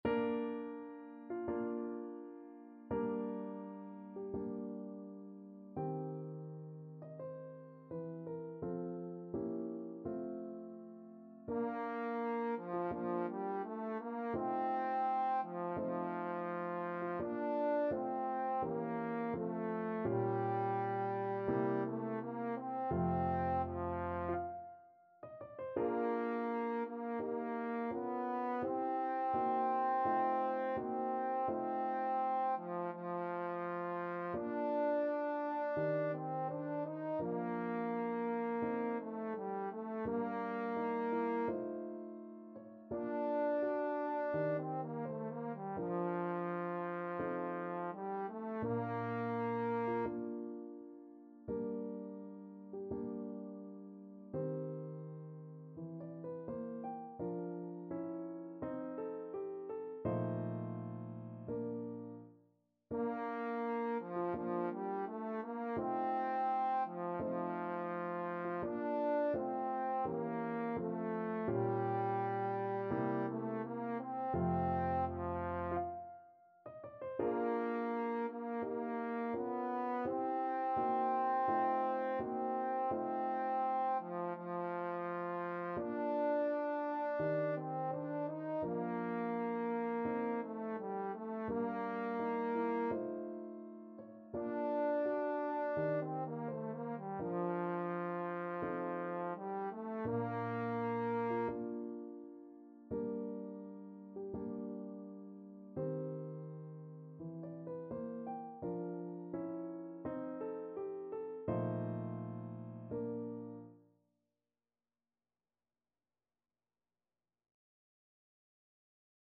Classical Schubert, Franz An die Sonne, D.270 Trombone version
Trombone
2/4 (View more 2/4 Music)
Bb major (Sounding Pitch) (View more Bb major Music for Trombone )
~ = 42 Sehr langsam
Classical (View more Classical Trombone Music)